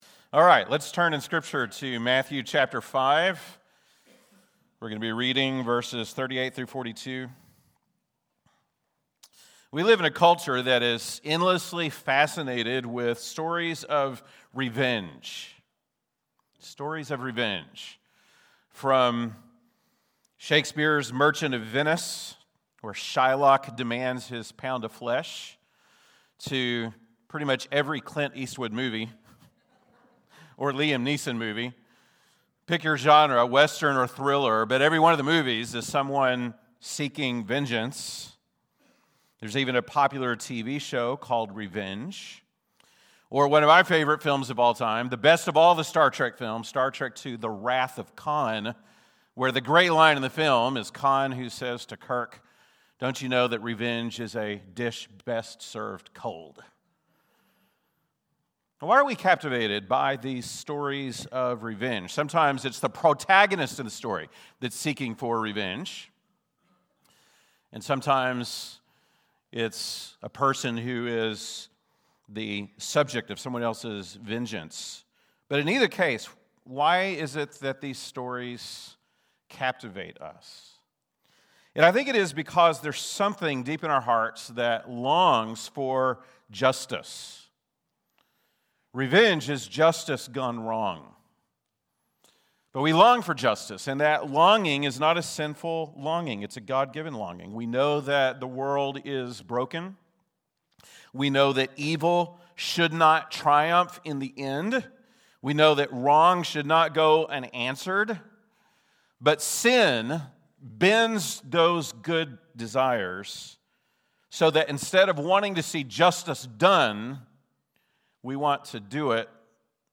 February 8, 2026 (Sunday Morning)